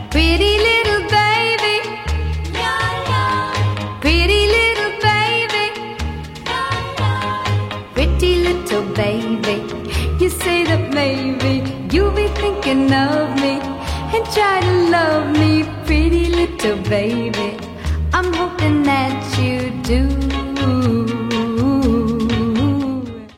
Ringtone File